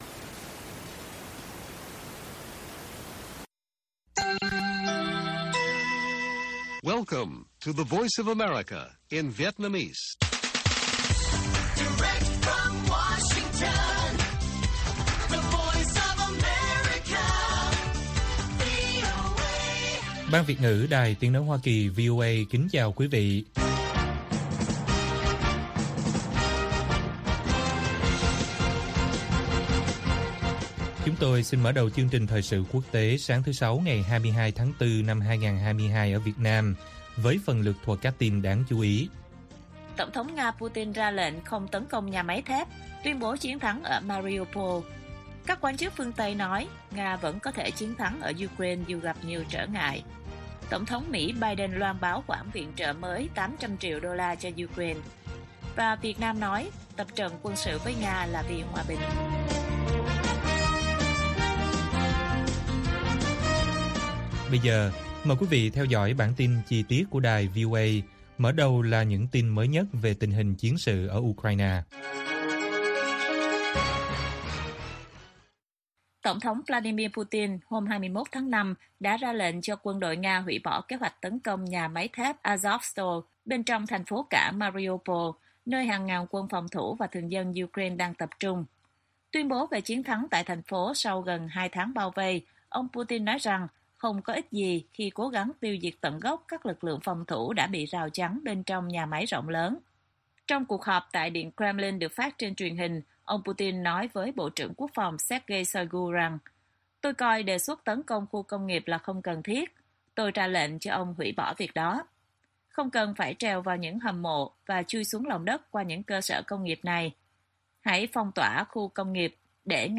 Putin ra lệnh không tấn công nhà máy thép, tuyên bố chiến thắng ở Mariupol - Bản tin VOA